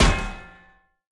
Media:BarbarianKing_super.wav 技能音效 super 铁拳击打敌人音效